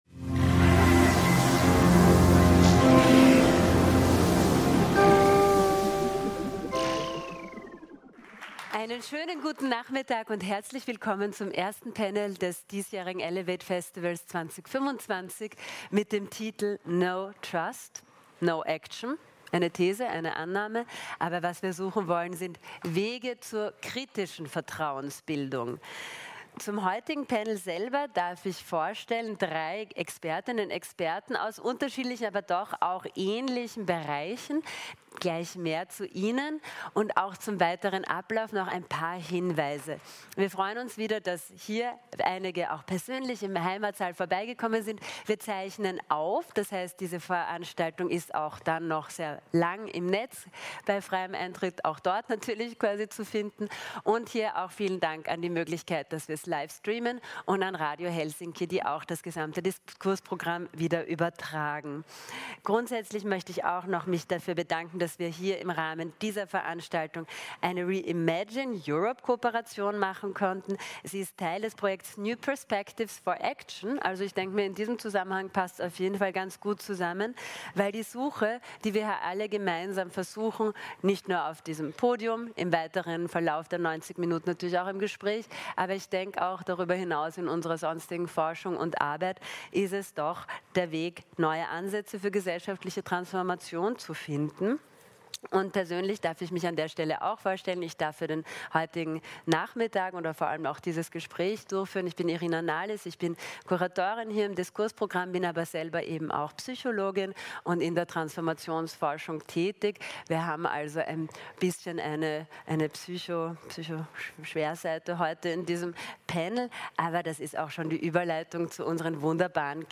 In diesem Gespräch wird der Blick auf die Bedingungen für Vertrauen und die Herausforderungen seiner Bildung gelenkt.